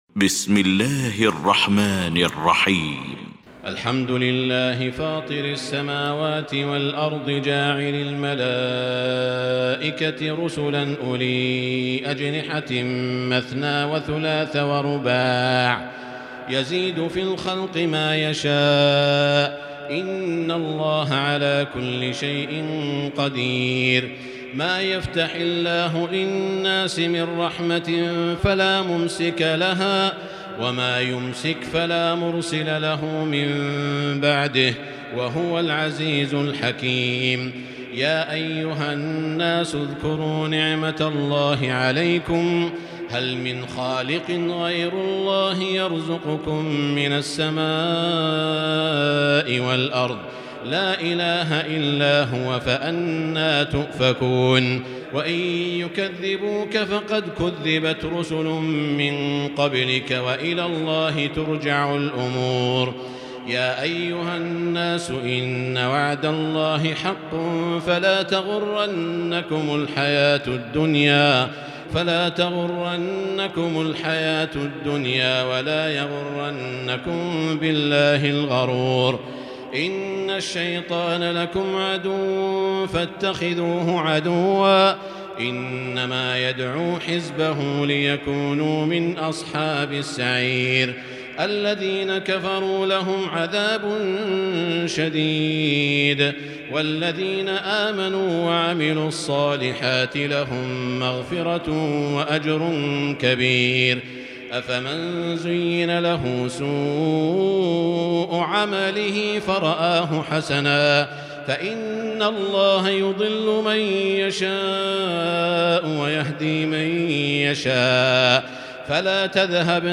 المكان: المسجد الحرام الشيخ: سعود الشريم سعود الشريم فاطر